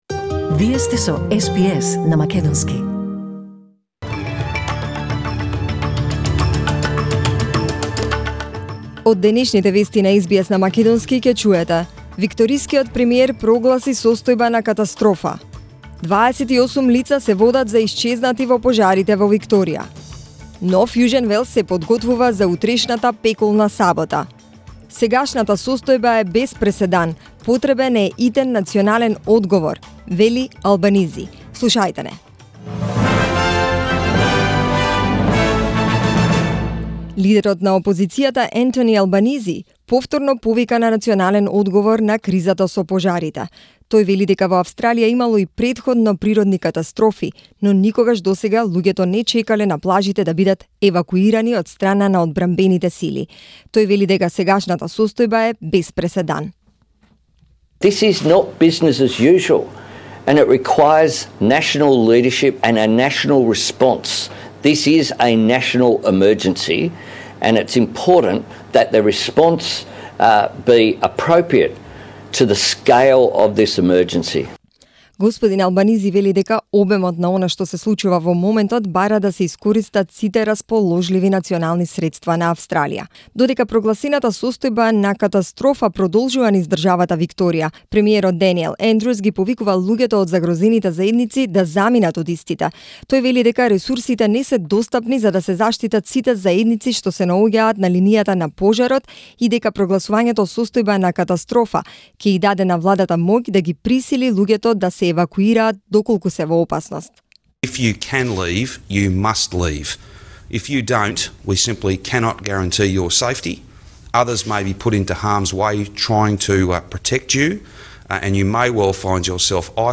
SBS News in Macedonian, 3rd January 2020